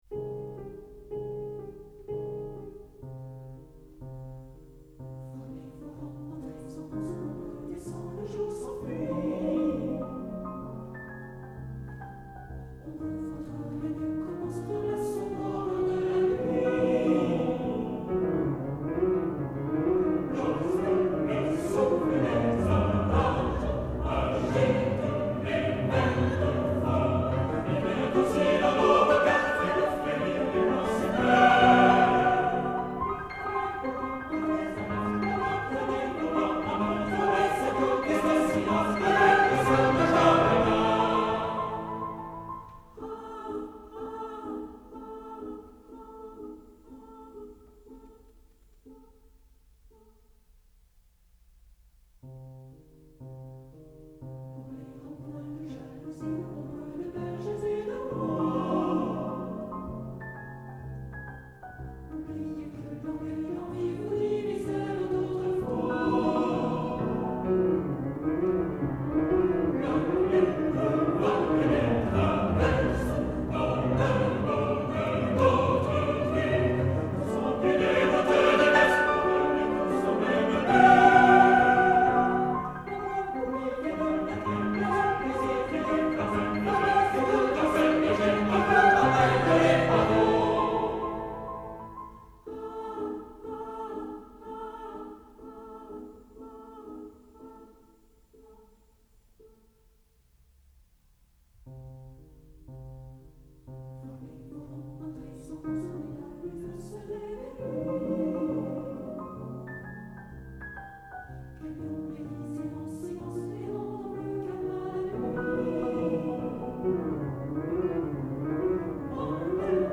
Published in 1829 as Opus 2, and then withdrawn from publication by Berlioz, Le Ballet des ombres, ronde nocturne for chorus (STTBB) and piano is an important early work due to its descriptive element.
Berlioz prefaced the score with a selection from act 3, scene 3 of Shakespeare’s Hamlet.2 At the end of the first choral phrase the divisi tenors and basses sing the text “Hou!” while their harmony shifts from a diminished seventh chord to a tritone.
Within the score Berlioz indicates portamento between the two harmonic structures.3 At the end of each of the three strophes the sopranos and first tenors sing a descending portamento semi-tone figure on the word “Ah,” lamenting their non-corporeal status.